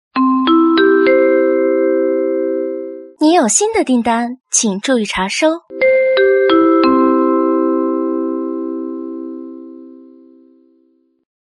ringing.mp3